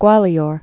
(gwälē-ôr)